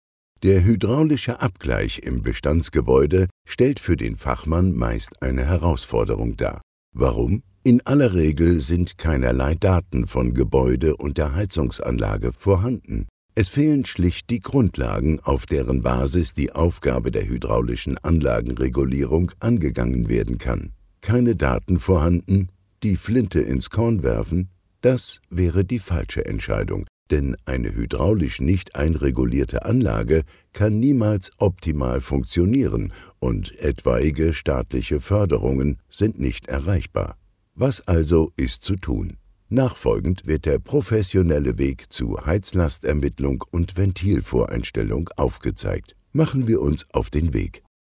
Profisprecher deutsch. Angenehme tiefe Stimme, weiche dunkle Stimme, Seniorstimme, young senior
Sprechprobe: eLearning (Muttersprache):